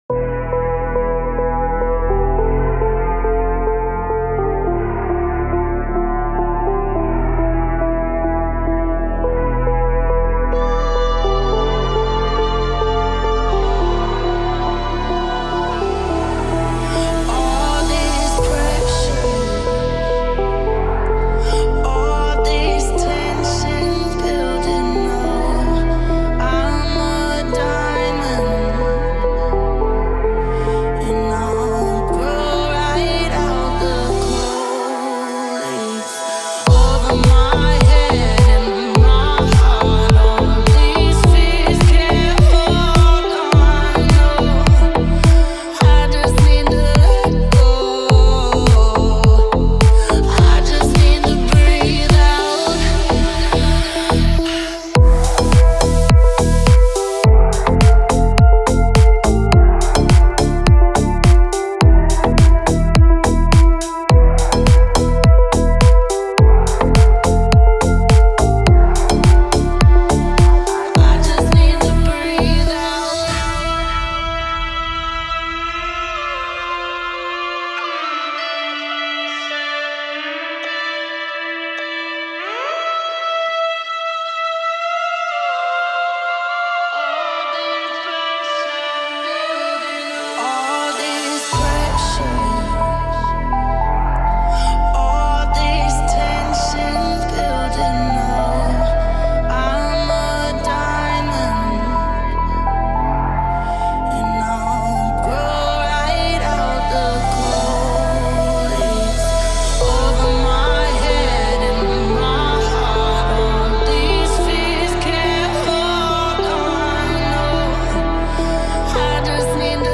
это эмоциональная композиция в жанре поп